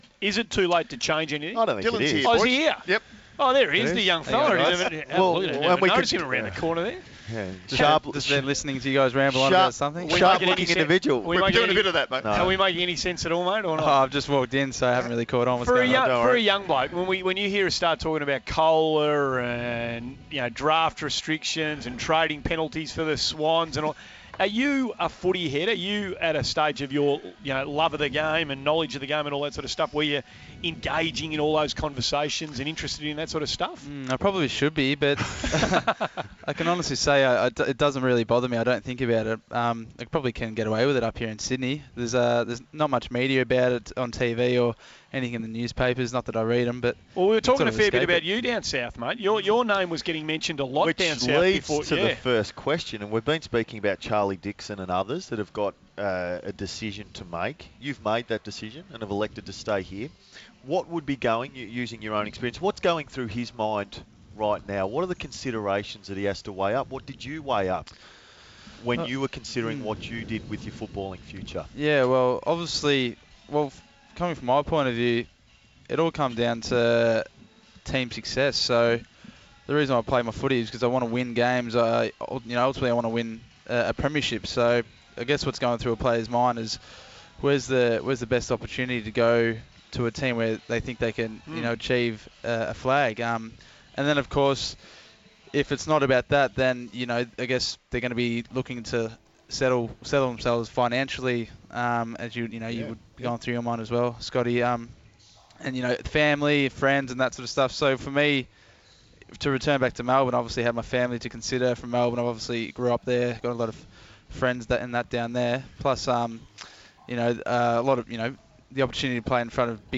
Dylan Shiel joins the commentary box
Injured GWS Giants midfielder Dylan Shiel joined our commentators in the box to discuss the road ahead for his club.